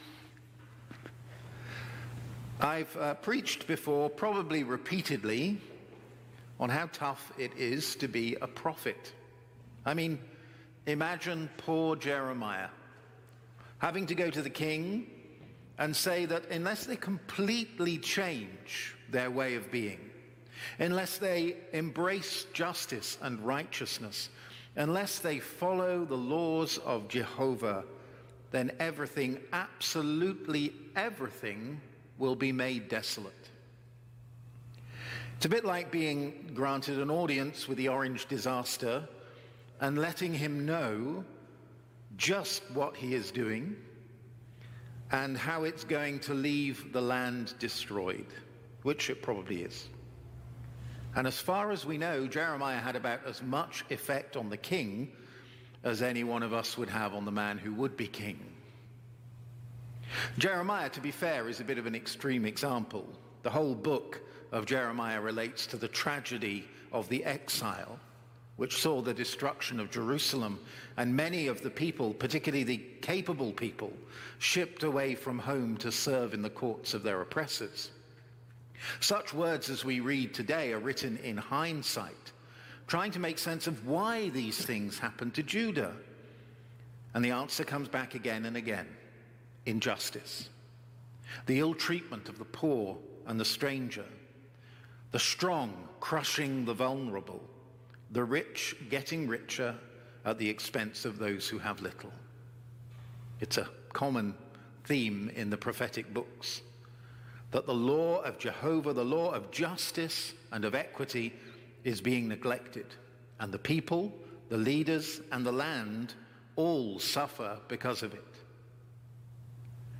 Sermons | St. John the Divine Anglican Church
Evensong Reflection